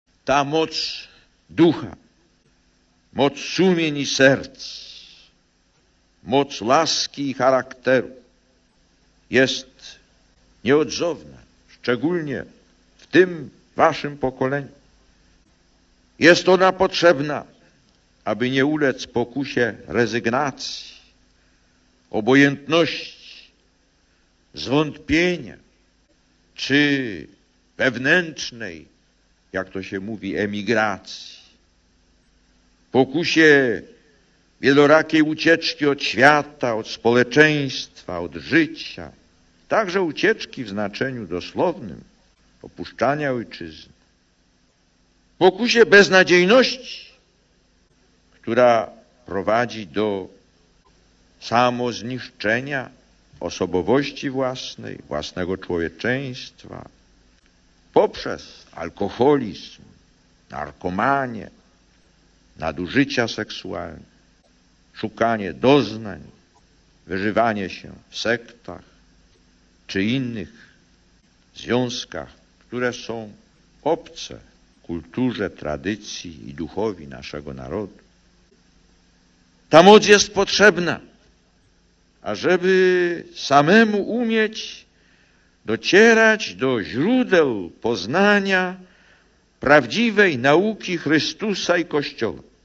Lektor: Z homilii podczas liturgii słowa na Westerplatte (Gdańsk 12.06.1987 – nagranie): „Młody człowiek pyta Chrystusa: "Co mam czynić, aby osiągnąć życie wieczne?".